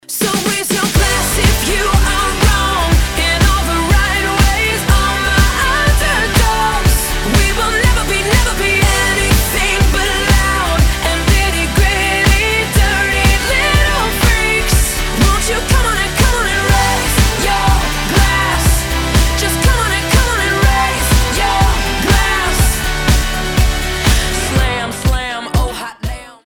Speváčka